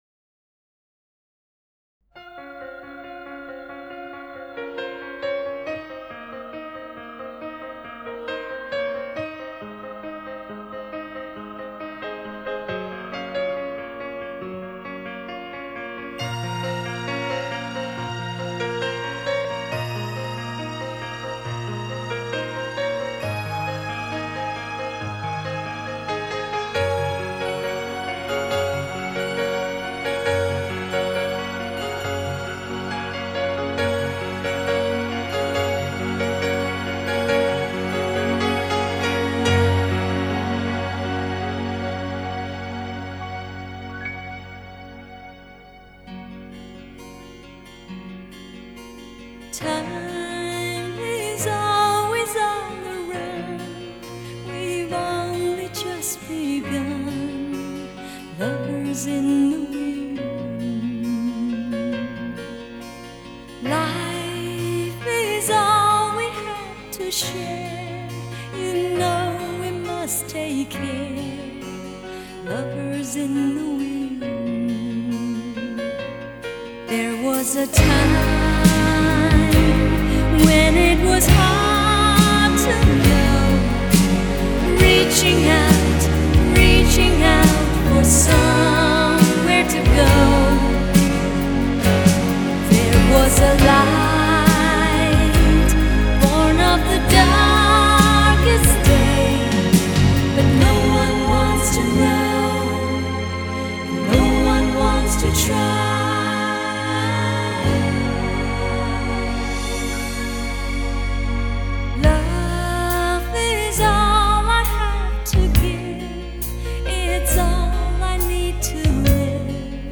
Жанр: Chinese pop / Pop